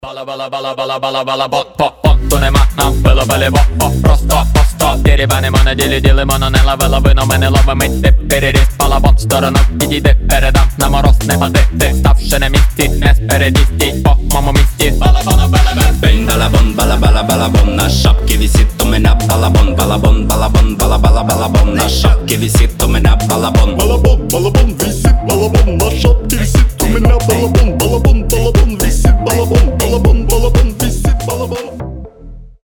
• Качество: 320, Stereo
веселые
басы
качающие
house
смешные